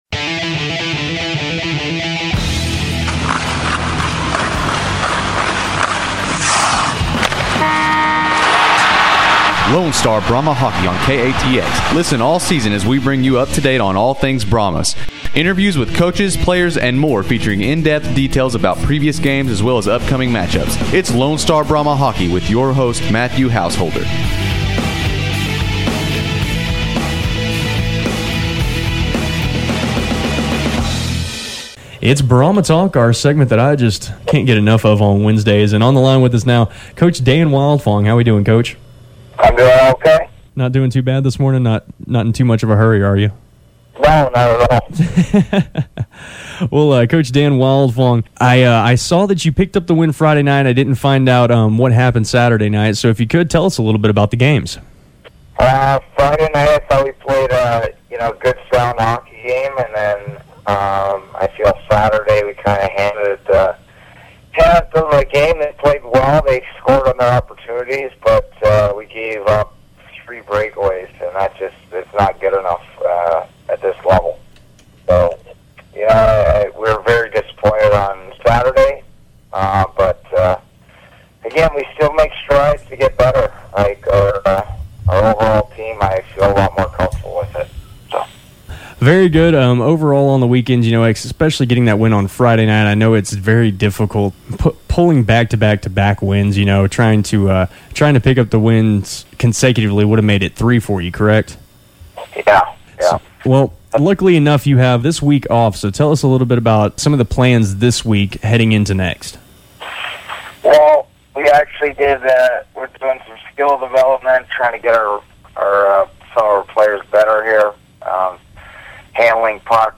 (audio rough in first min but clears up)